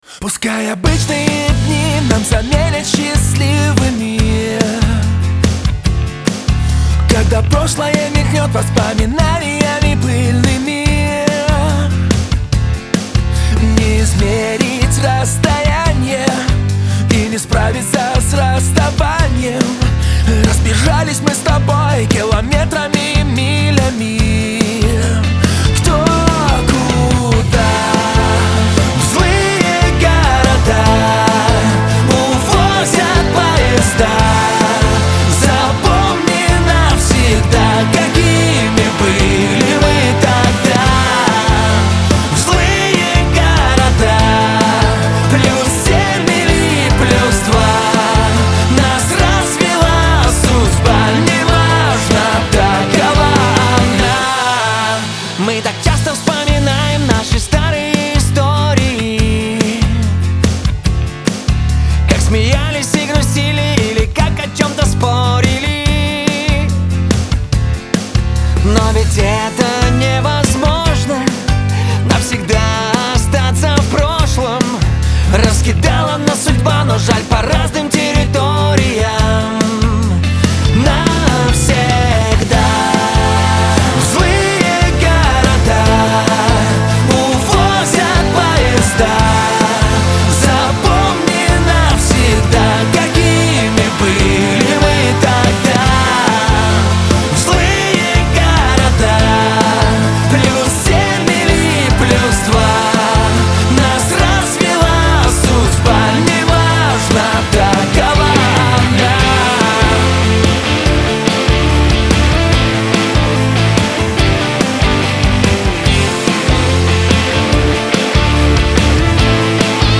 Лирика
дуэт , ХАУС-РЭП